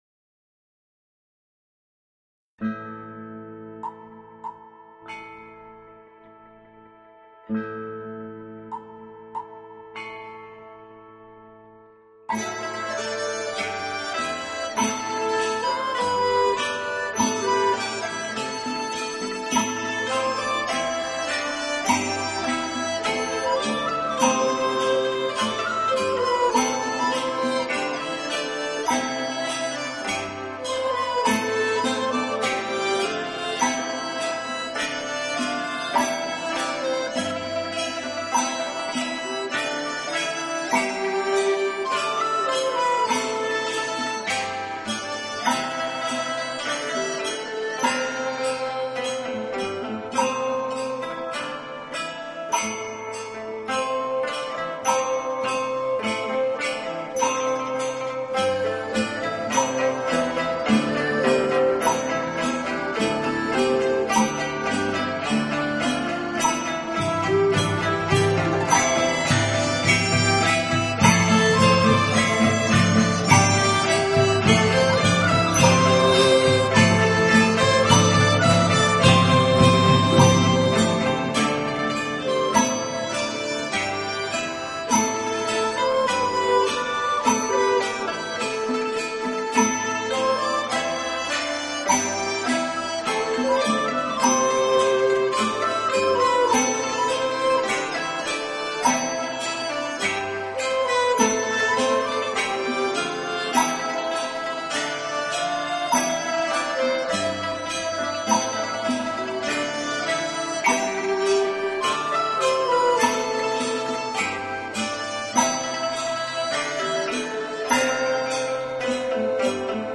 《玄风遗韵》是成都青羊宫道乐团演奏的道乐乐曲。
其传承道乐为广成韵之代表。